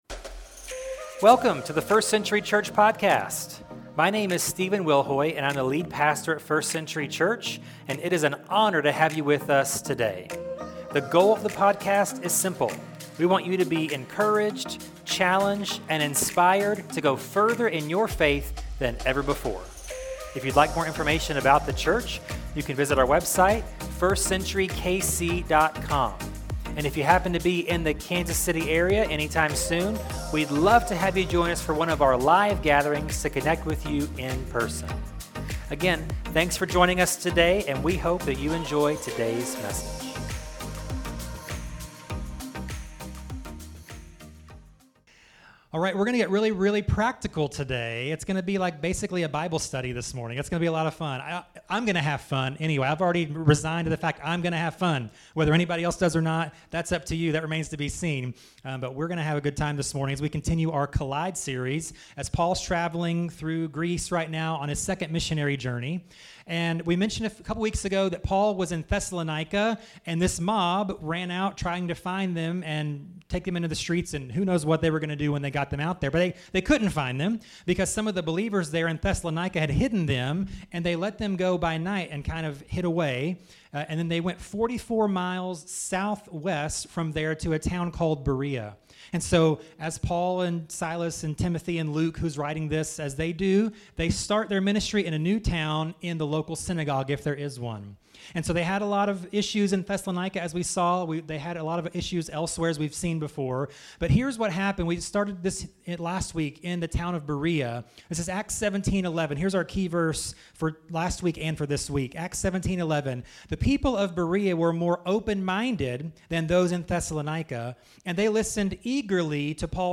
The Bible is a unique book in many ways, and honestly it can be difficult to read. This practical message will help you learn how to read, understand and apply the Bible to your life.